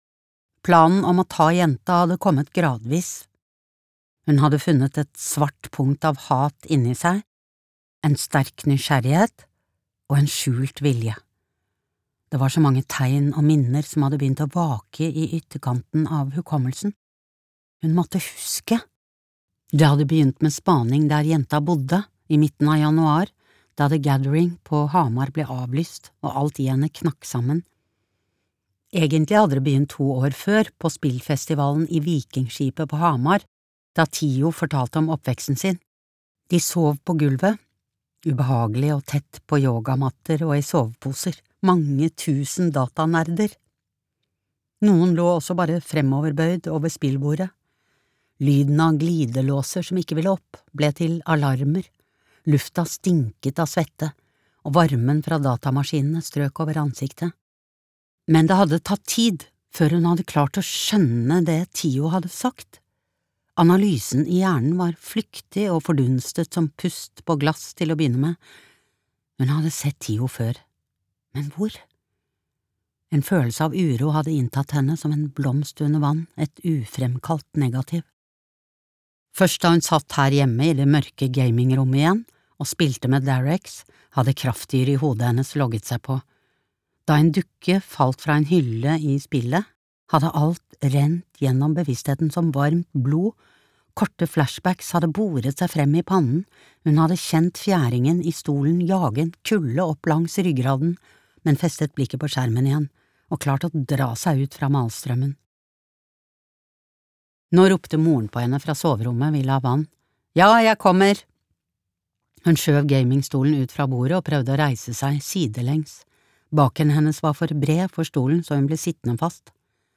Dukken i tauet (lydbok) av Unni Lindell